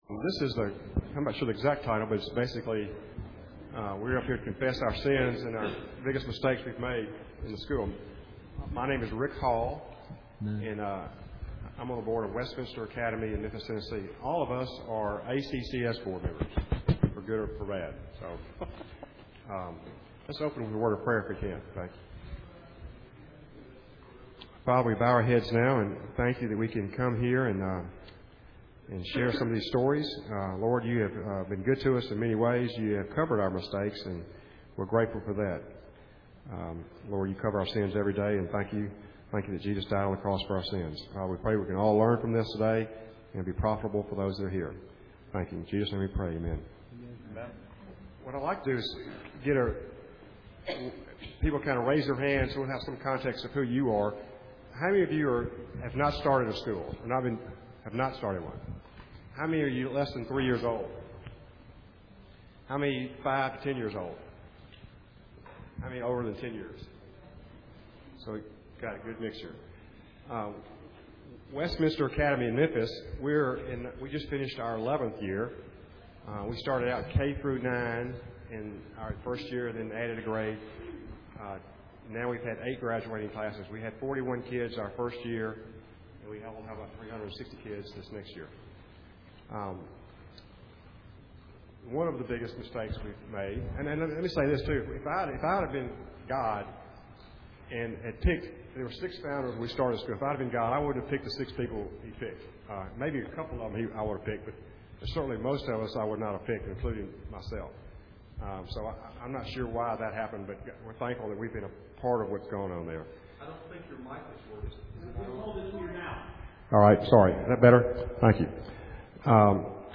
2007 Workshop Talk | 0:56:45 | Leadership & Strategic
The Association of Classical & Christian Schools presents Repairing the Ruins, the ACCS annual conference, copyright ACCS.
Confessions - Biggest Mistake We Made ACCS Panel.mp3